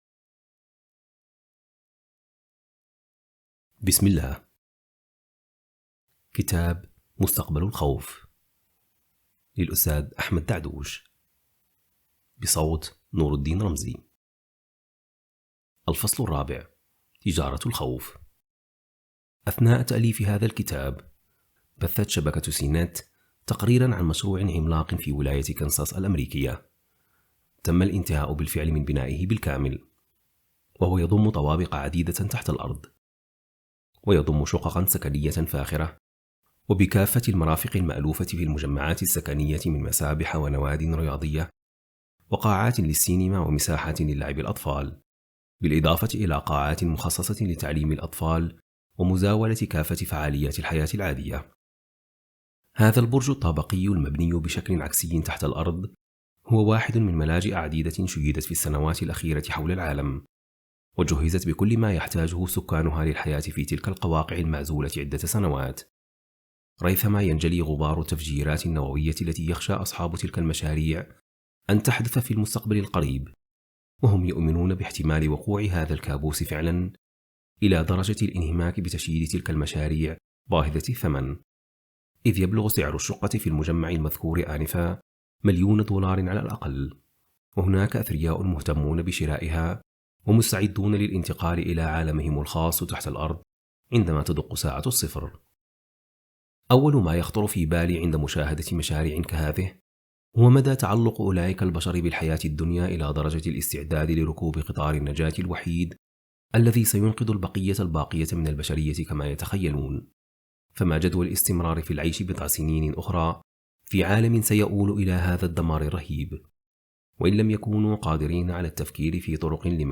كتاب صوتي| مستقبل الخوف (الفصل الرابع) • السبيل